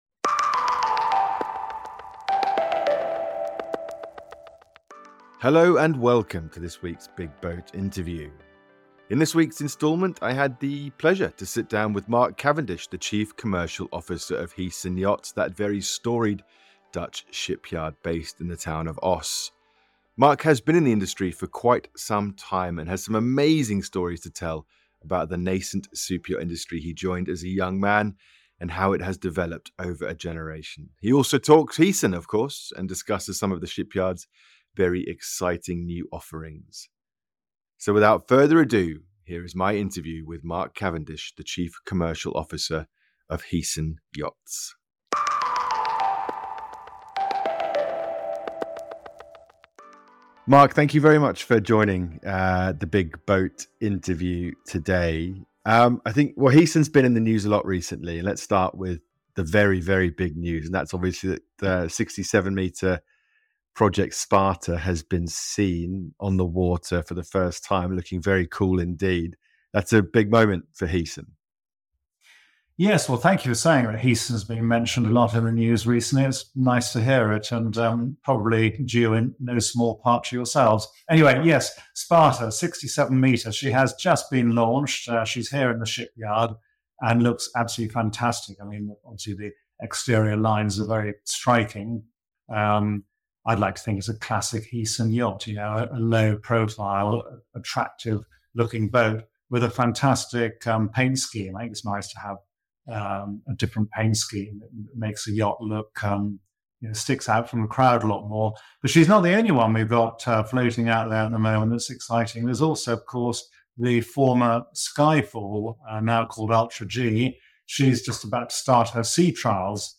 The Big BOAT Interview